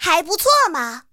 T-60获得资源语音.OGG